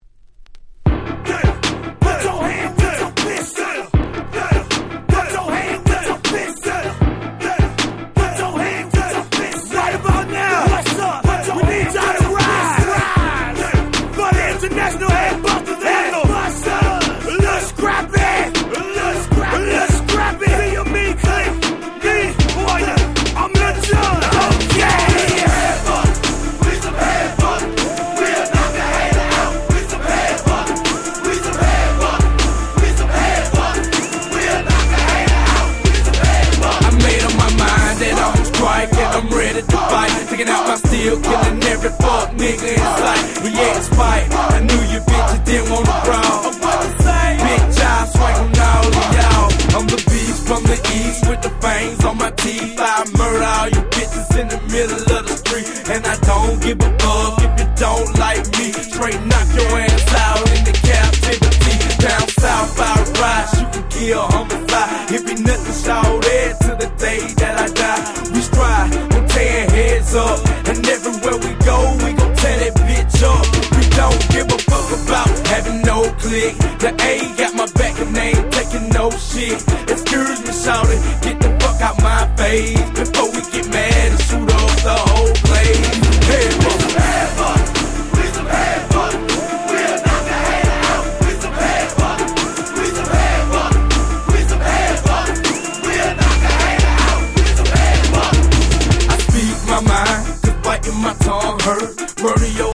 03' Smash Hit Southern Hip Hop !!